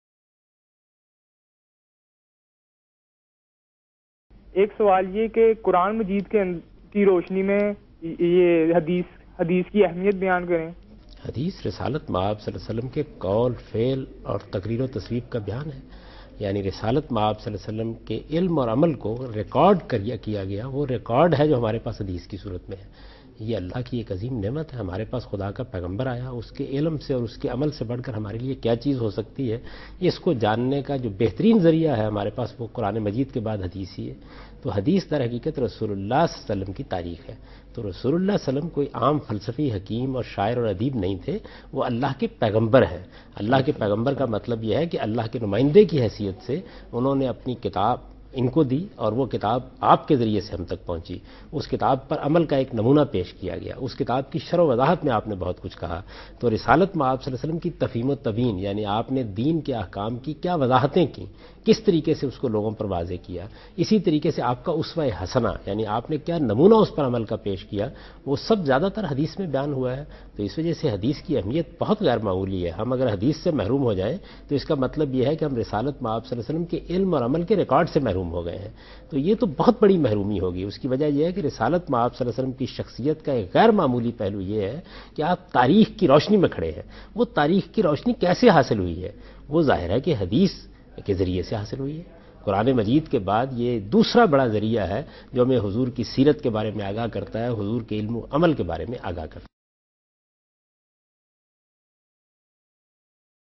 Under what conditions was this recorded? TV Programs